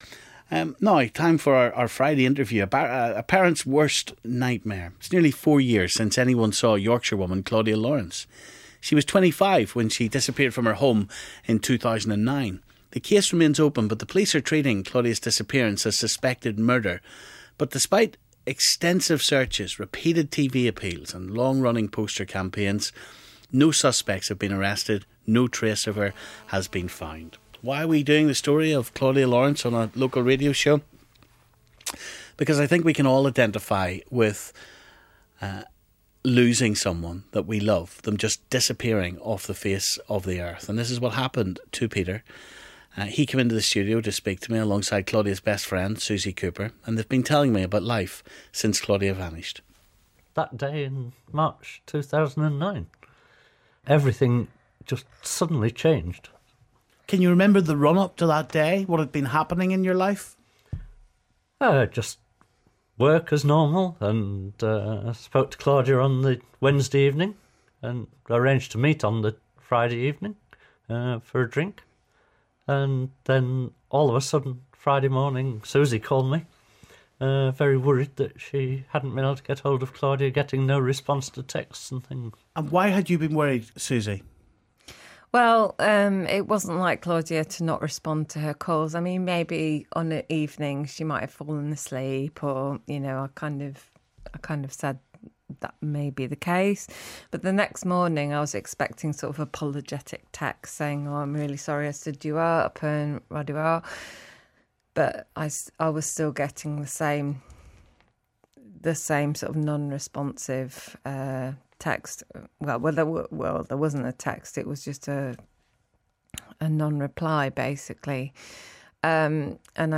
Our Big Friday Interview